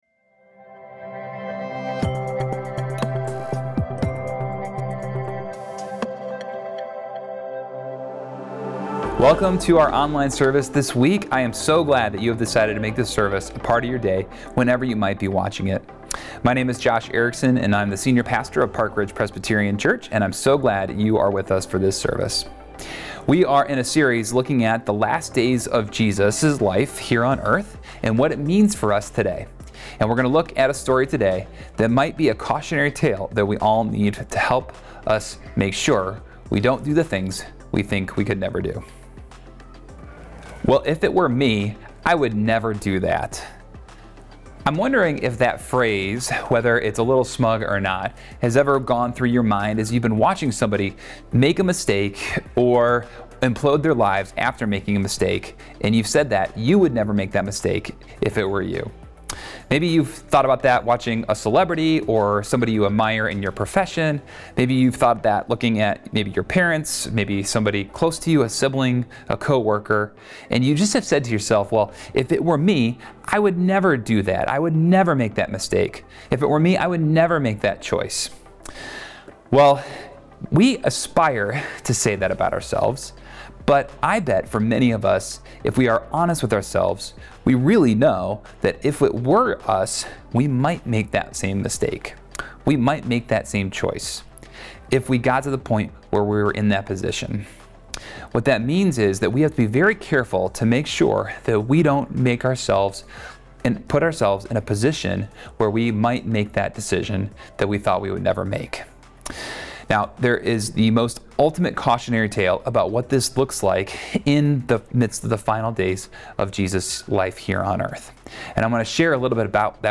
April 6, 2025 Online Worship Service